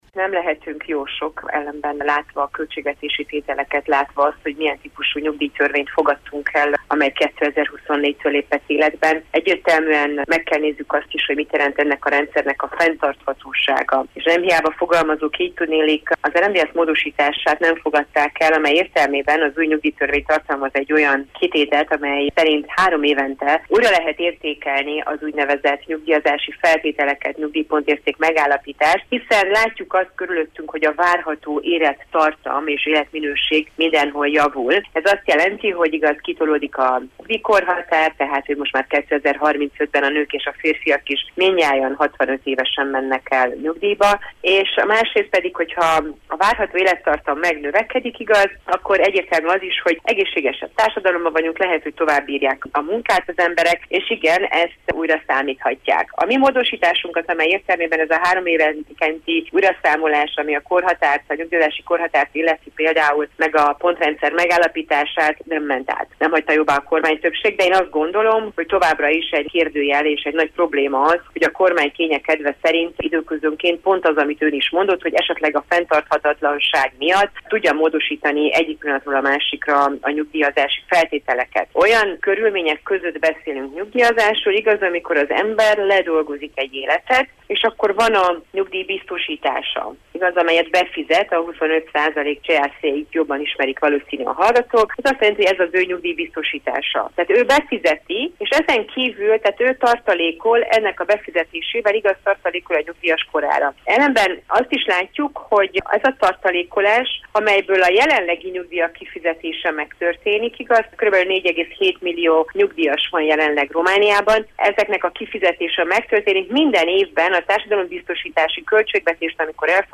Csép Éva Andrea parlamenti képviselő, szociális szakpolitikus